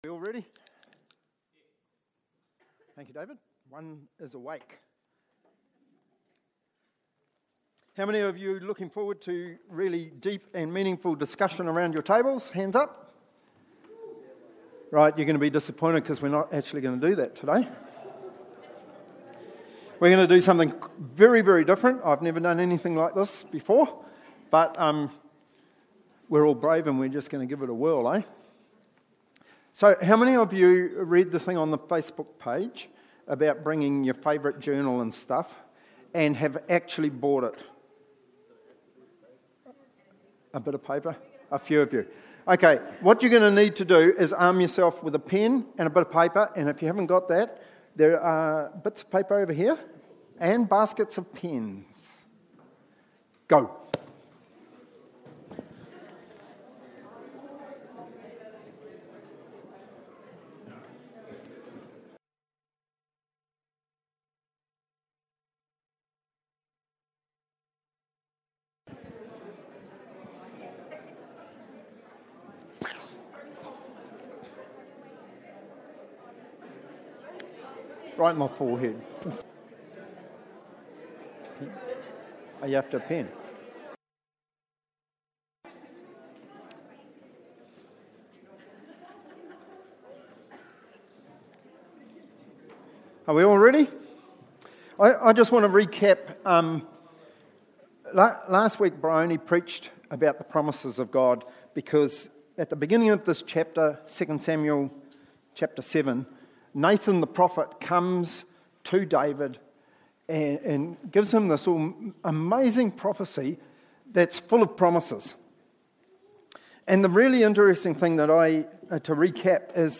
Service Type: Family Service